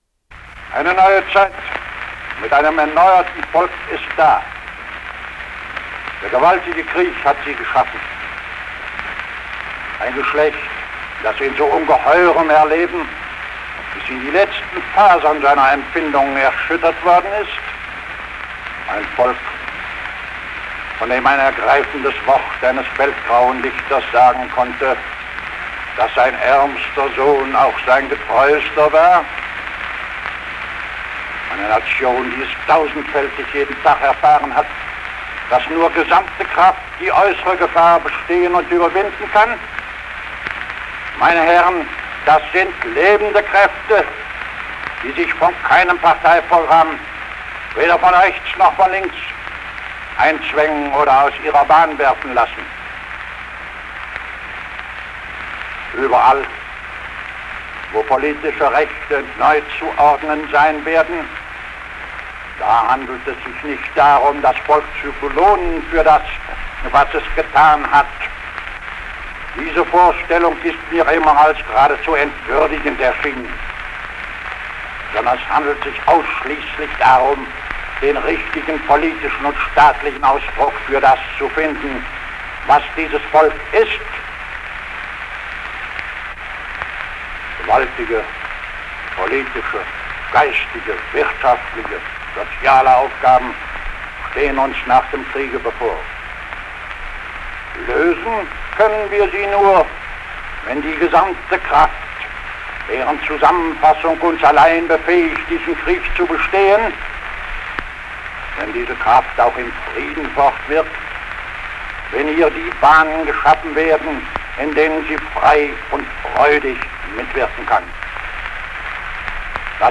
Address at the German Reichstag